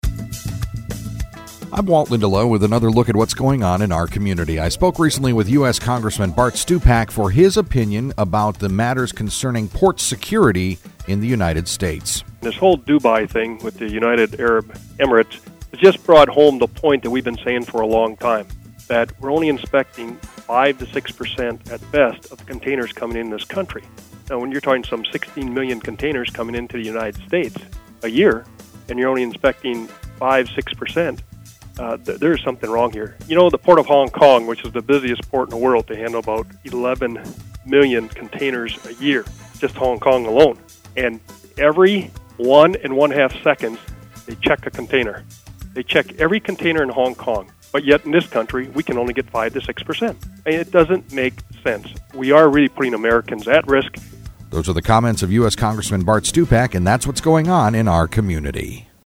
INTERVIEW: US Congressman Bart Stupak